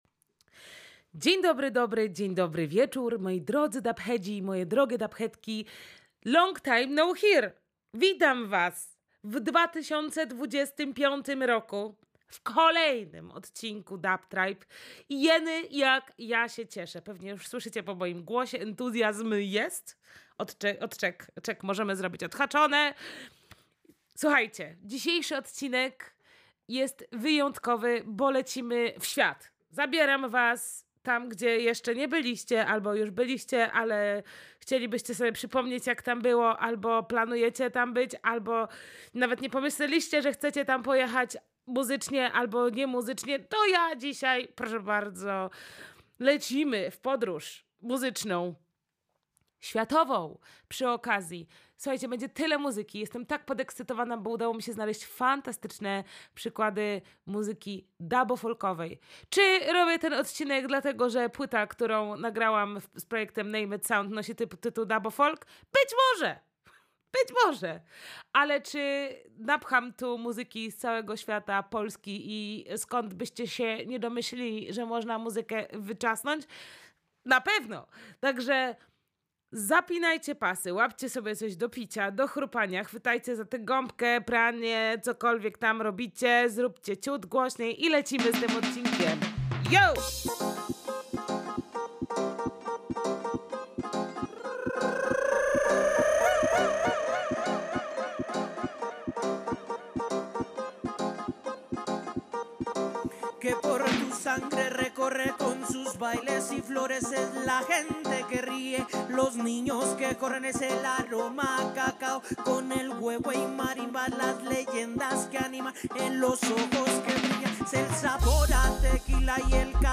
Pozwoliłam sobie na podróż przez Meksyk, Japonię, Nową Zelandię, Afrykę, Indie, Egipt, Syrię i Palestynę do Polski. Odcinek jest długi, ale mocno naładowany muzą i ciekawymi melodiami z kraju i ze świata.